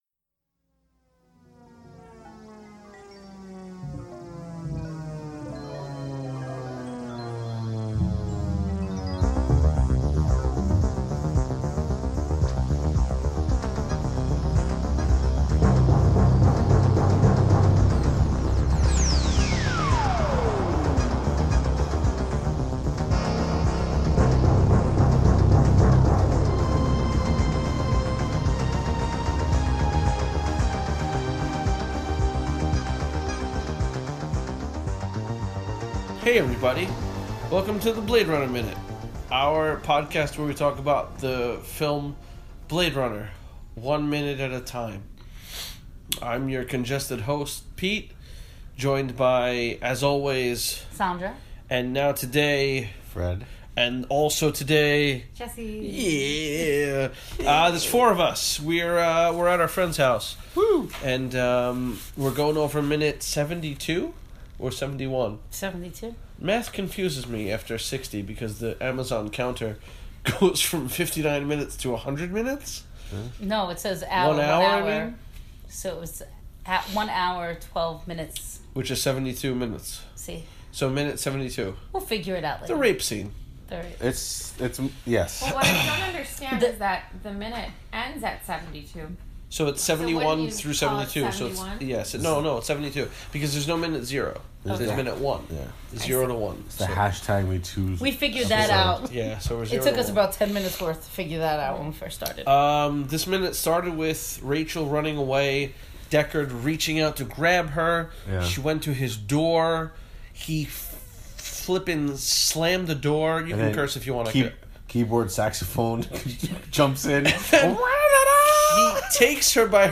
Here is the original recording of minute 72. I thought it was lost with my old hard drive but life found a way.